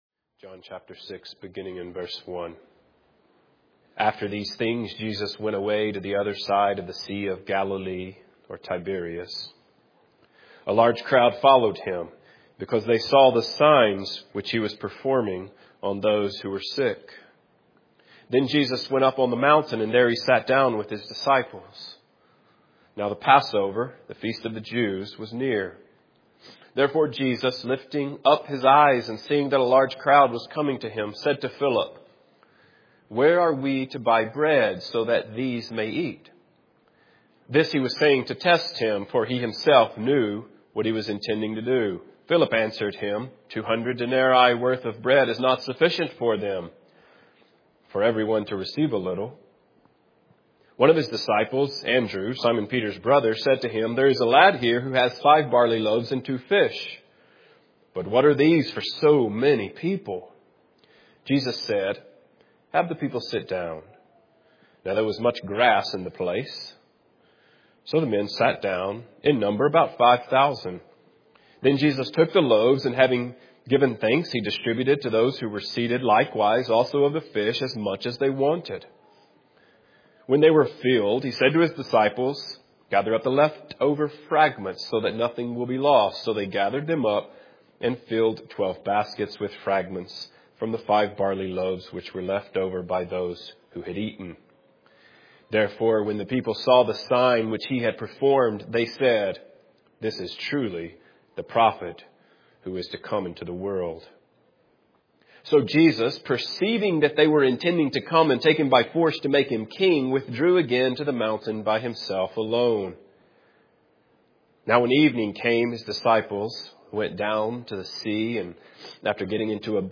Your Will Be Done | SermonAudio Broadcaster is Live View the Live Stream Share this sermon Disabled by adblocker Copy URL Copied!